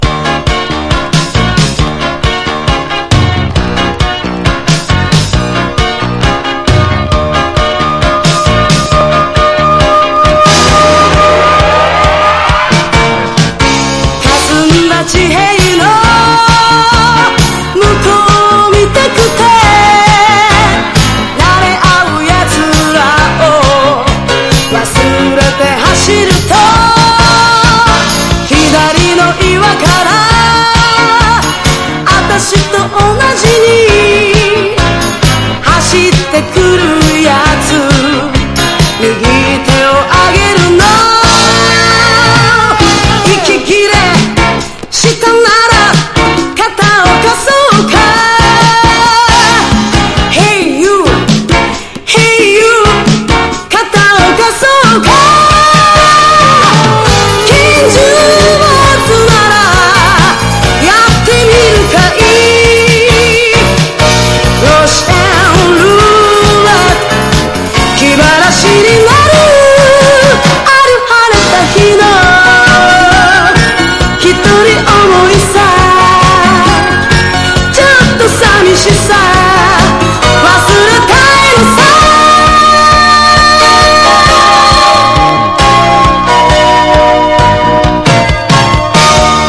POP
ポピュラー# SOUNDTRACK
• 盤面 : EX+ (美品) キズやダメージが無く音質も良好